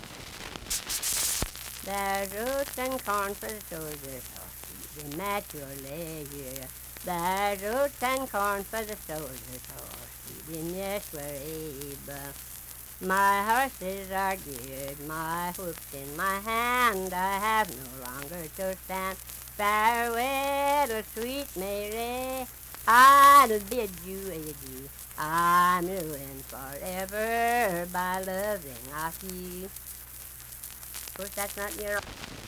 Unaccompanied vocal music
Voice (sung)